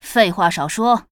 文件 文件历史 文件用途 全域文件用途 Cyrus_tk_02.ogg （Ogg Vorbis声音文件，长度1.1秒，118 kbps，文件大小：16 KB） 源地址:游戏语音 文件历史 点击某个日期/时间查看对应时刻的文件。